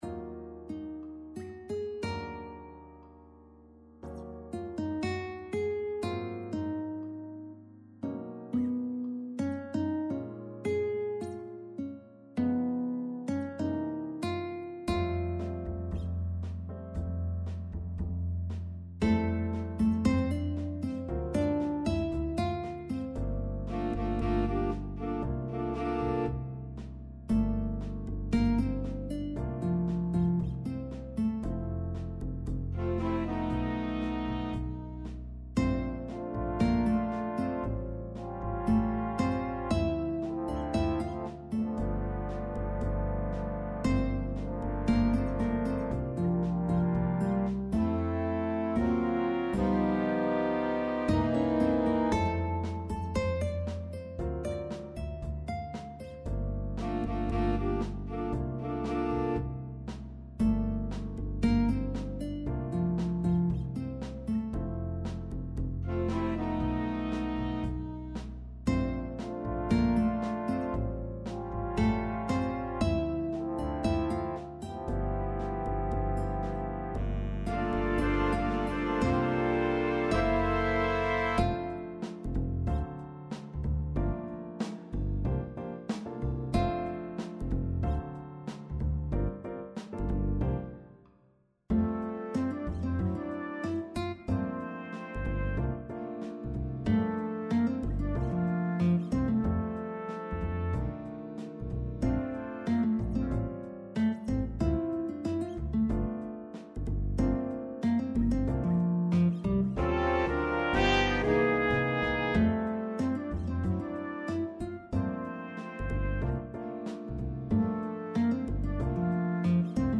Skladby pro Big Band / Big Band Scores
computer demo